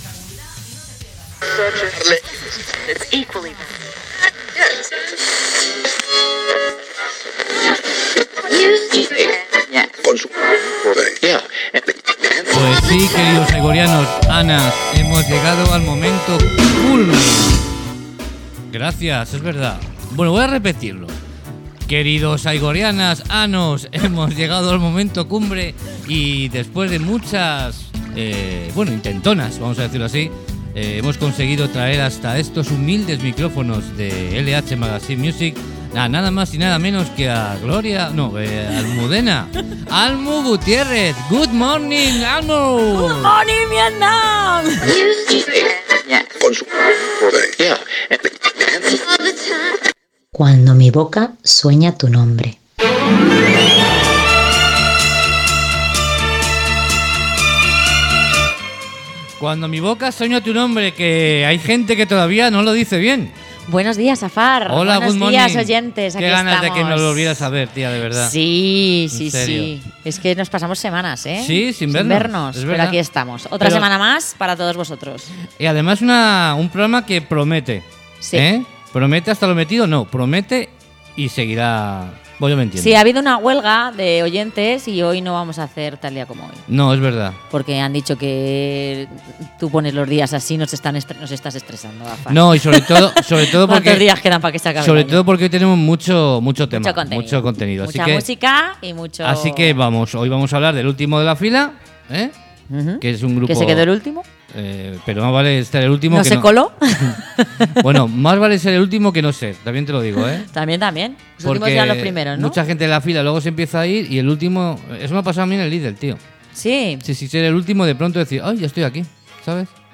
traemos un nuevo programa donde os mostramos una buena cantidad de proyectos musicales de nuestra escena en los que están invulcradas las mujeres y de esta manera suenan, entrevista a la banda sevillana black ducados